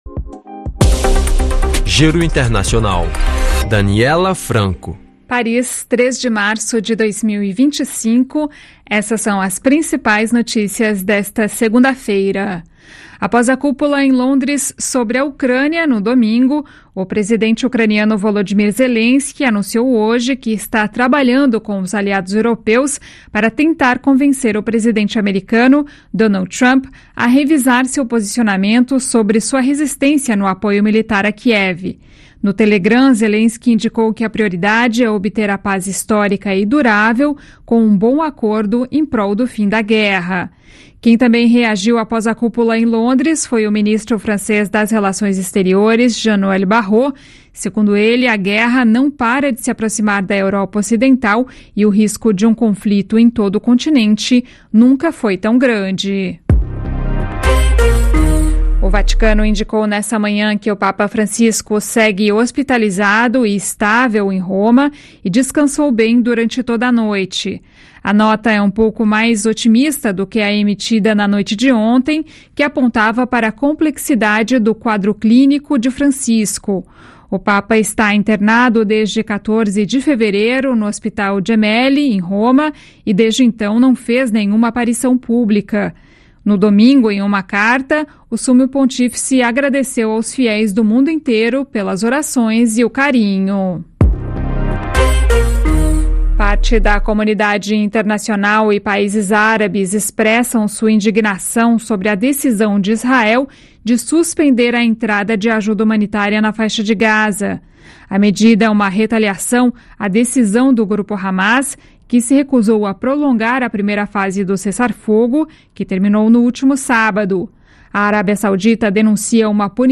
Flash de notícias 27/04 09h57 GMT.